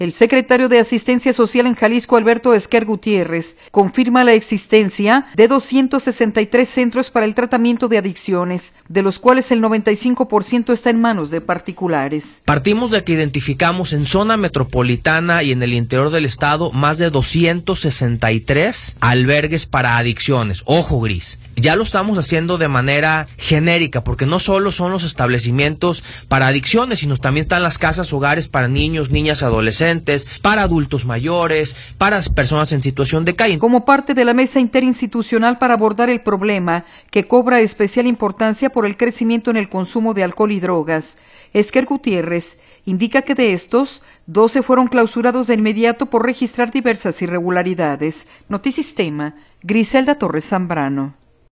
El secretario de Asistencia Social en Jalisco, Alberto Esquer Gutiérrez, confirma la existencia de 263 centros para el tratamiento de adicciones, de los cuales el 95 por ciento está en manos de particulares.